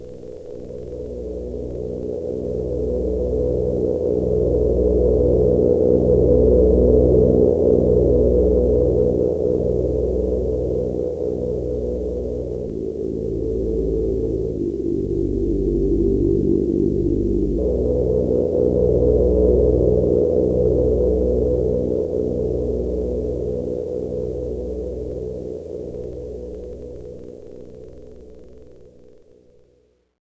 A combination of noise source and subtle filter resonance - the idea was for a soundtrack noise suitable for something like the Star Wars "Death Star" giant_sp_stn.mp3
A variation on the above (and at a higher frequency range) space_cruiser.mp3
space_cruiser.wav